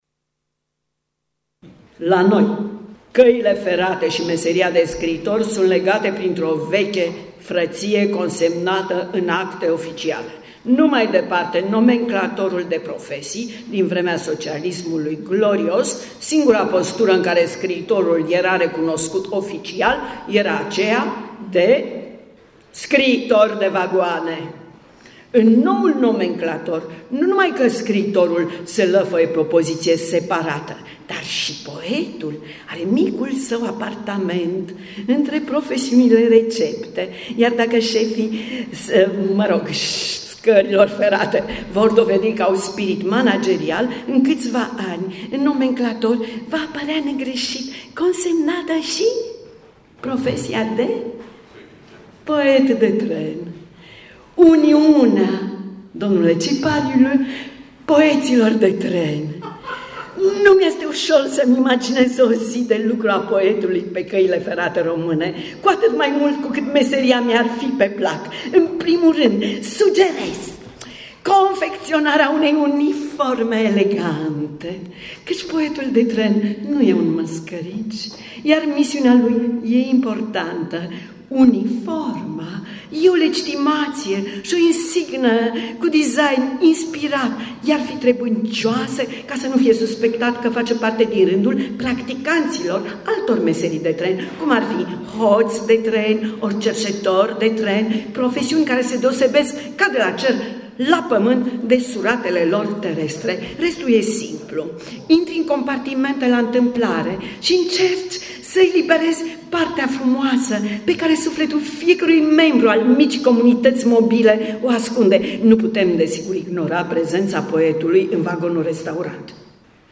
A fost doar o glumă,cu respirațoie poetică, ieșită de sub pana poetului Ion Mureșan și în rostirea actriței Ioana Crăciunescu.
Recitalul „Poetul de tren” s-a auzit în premieră la Turnirul Scriitorilor de la Sighișoara, ediția 2015.